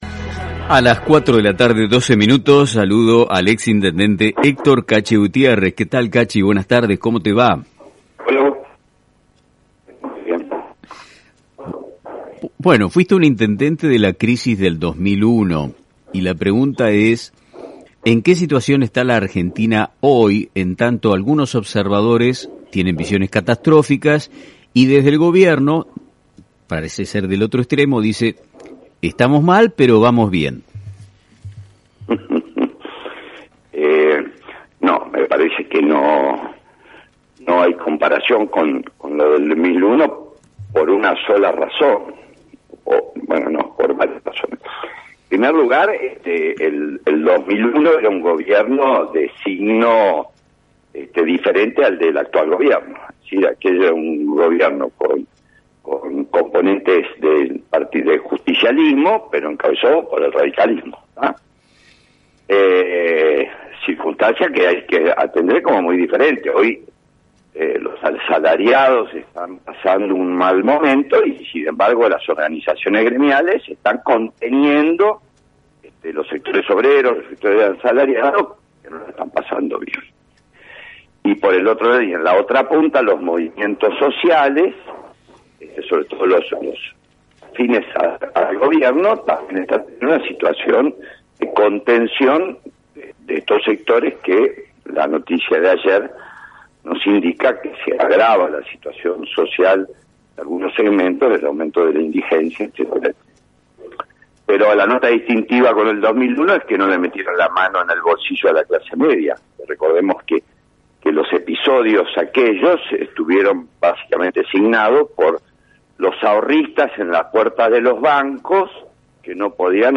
El ex intendente de Pergamino y exdiputado Nacional, Héctor Gutiérrez dialogó con «Nuestro Tiempo de Radio», y dejó su mirada sobre la situación del país.